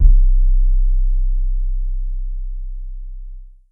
Quake808_YC.wav